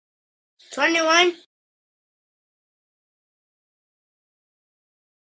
21 MLG (HD) sound effects free download
You Just Search Sound Effects And Download. funny sound effects on tiktok Download Sound Effect Home